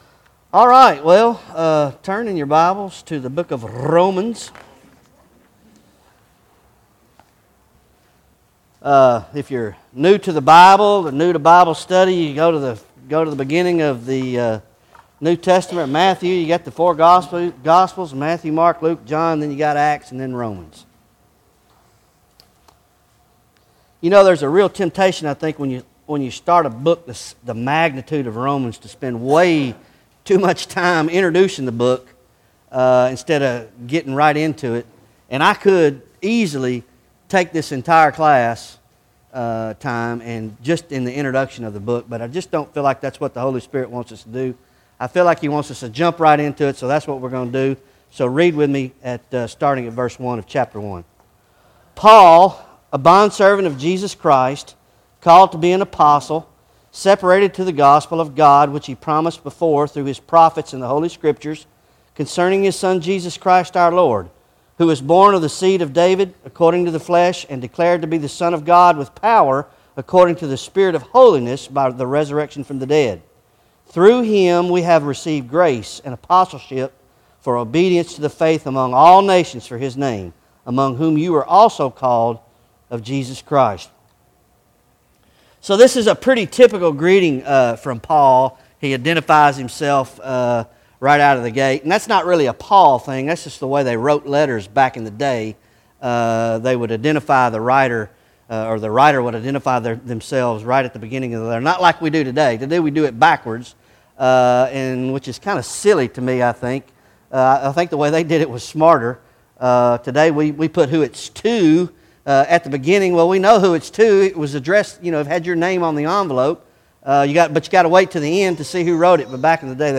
Bible Study Romans Ch 1